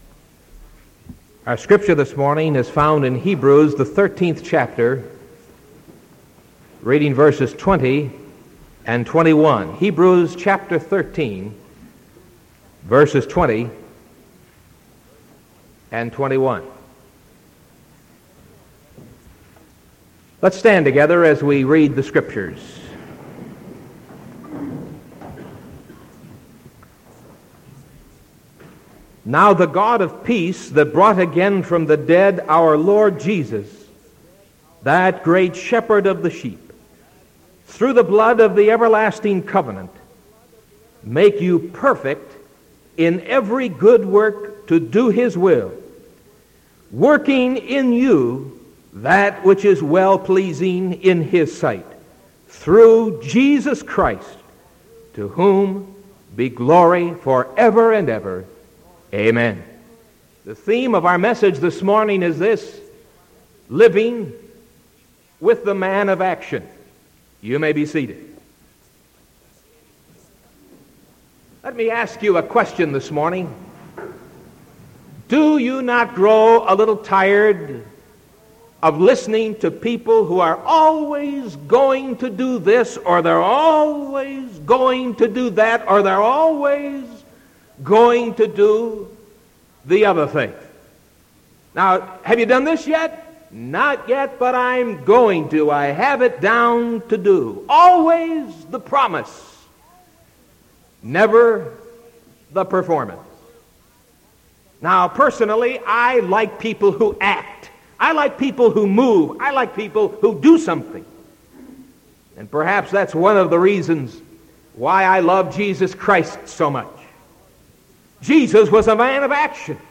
Sermon July 6th 1975 AM